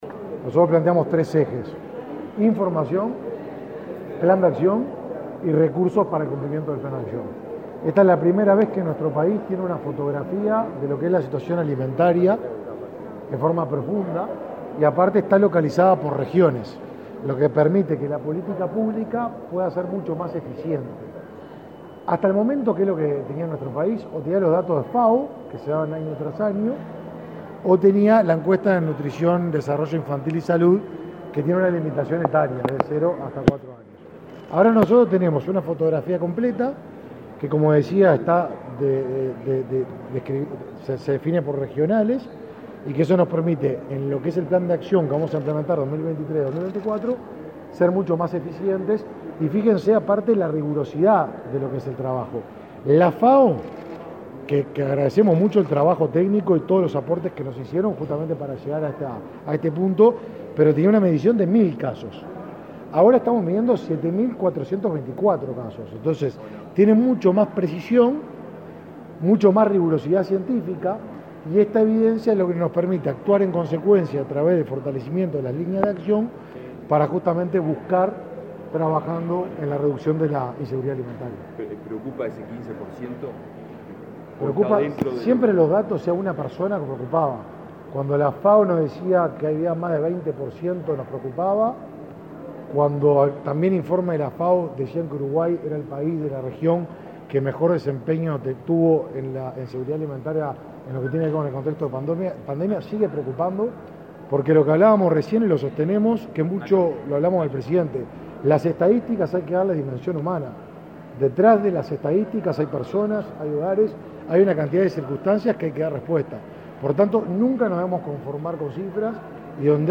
Declaraciones a la prensa del ministro de Desarrollo Social, Martín Lema
Luego dialogó con la prensa.